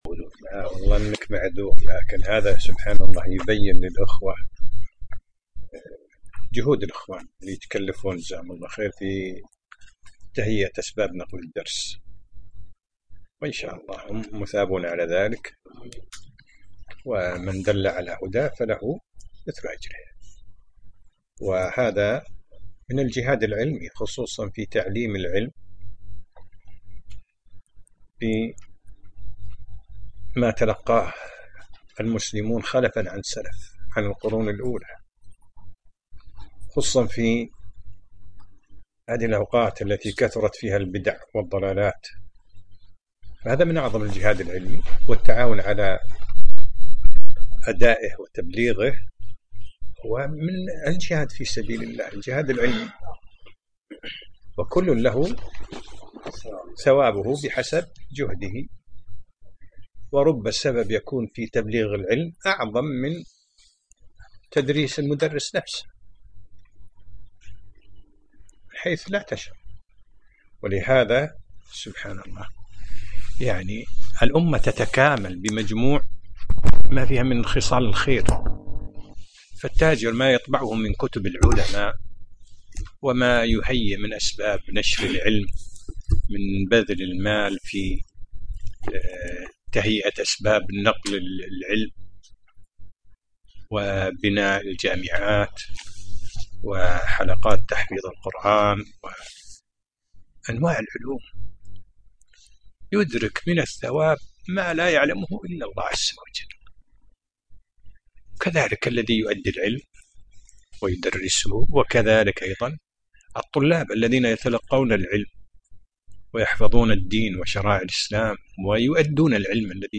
الدرس العاشر : فصل تفسير القرآن بأقوال التابعين